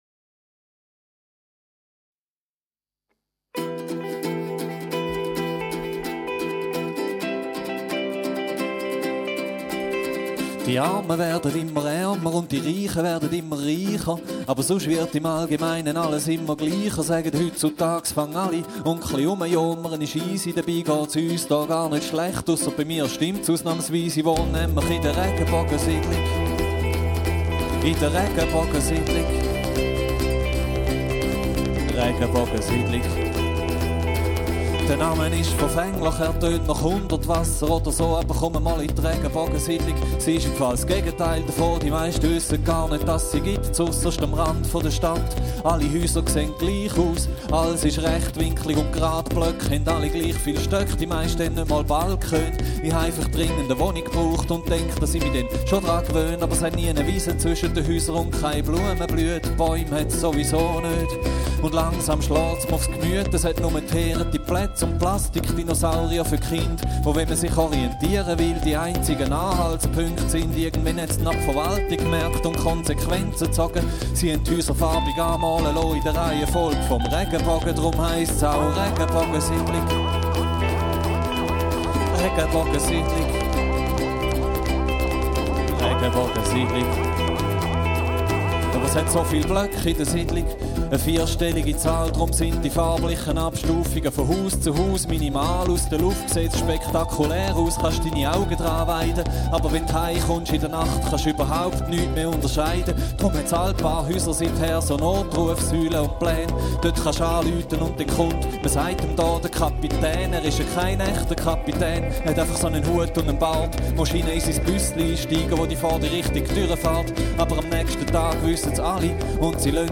Wortwitz und Biomusik
Gitarre, Piano, Gesang
Perkussion, Gitarre, Gesang
mit akustischen Instrumenten einen dynamischen Sound